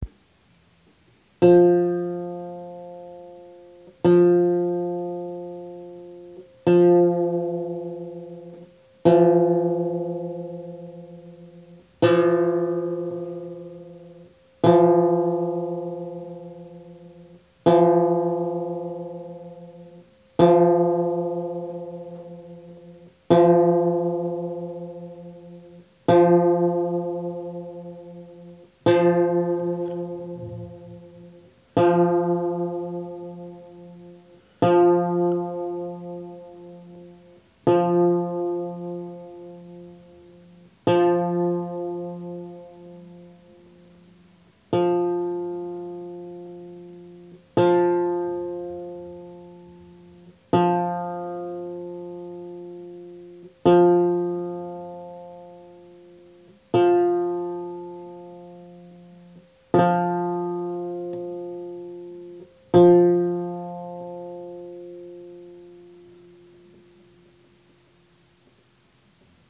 Pongo un ejemplo de afinación de un unísono, en concreto del cuarto orden de mi vihuela (F3).
Al principio está casi afinado, luego lo he desafinado y me he ido aproximando otra vez lentamente para que se escuchen bien los batimentos y la sonoridad que se produce. Al final se perciben ambas cuerdas como una sola y se pueden escuchar con cierta claridad los dos primeros armónicos.
afinando-un-unisono.ogg